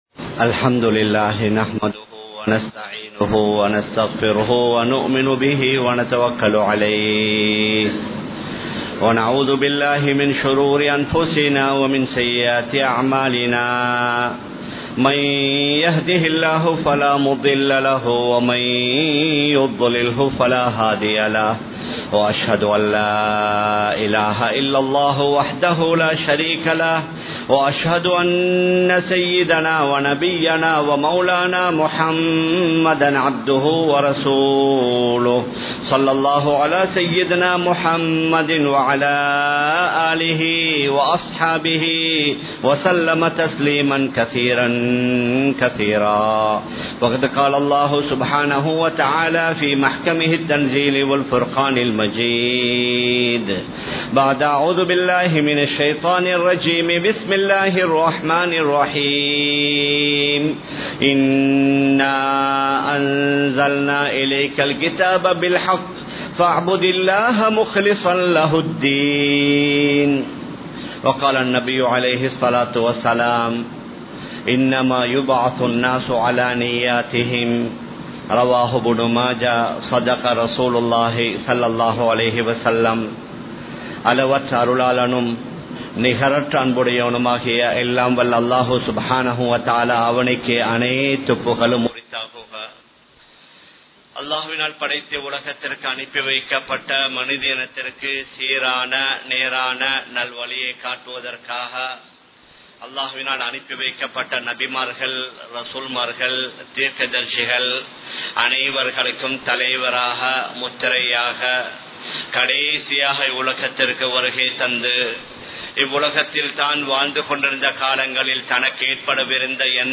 நிய்யத்திற்கான கூலி | Audio Bayans | All Ceylon Muslim Youth Community | Addalaichenai
Kollupitty Jumua Masjith